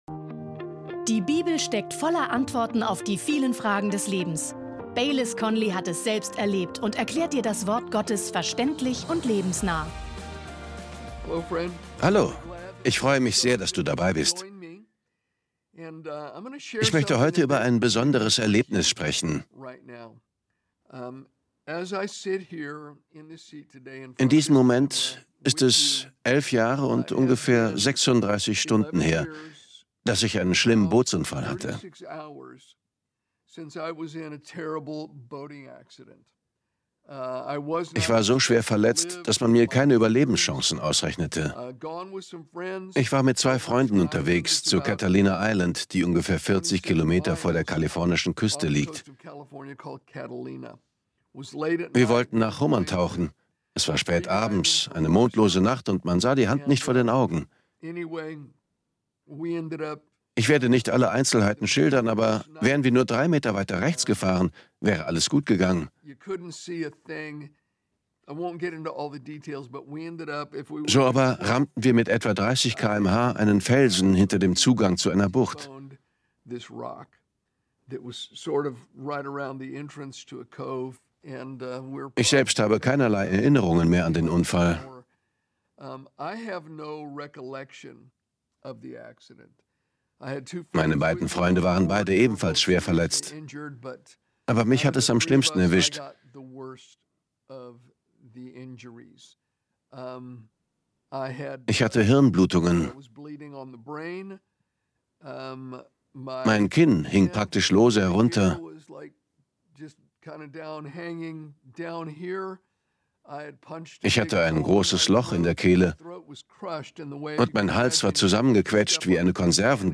Sehr persönlich und berührend erzählt er in diesem Video, wie Gottes Zusagen ihm in seinen dunkelsten Stunden Halt gaben – und ermutigt dazu: Verlasse dich auf Gottes unveränderliche Treue!